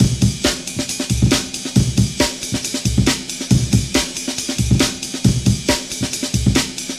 69 Bpm Breakbeat Sample C Key.wav
Free breakbeat - kick tuned to the C note.
69-bpm-breakbeat-sample-c-key-7vk.ogg